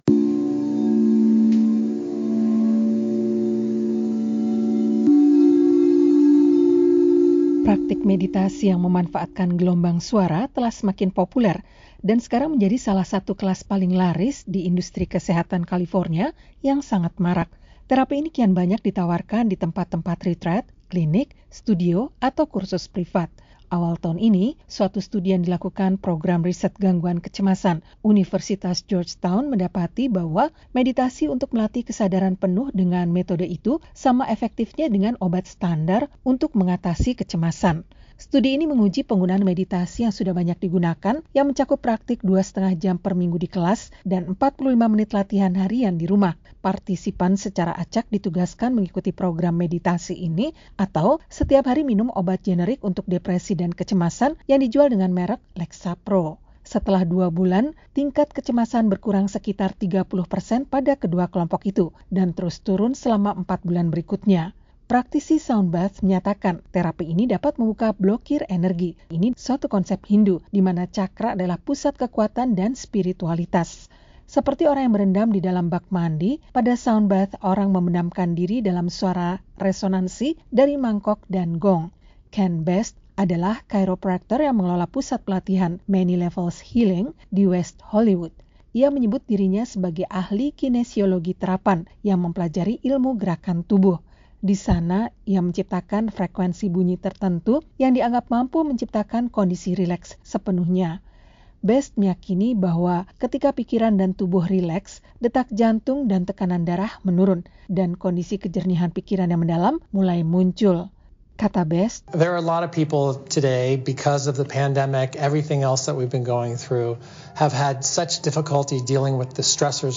Seperti orang yang berendam di dalam bak mandi, pada sound bath orang membenamkan diri dalam suara resonansi dari mangkok dan gong.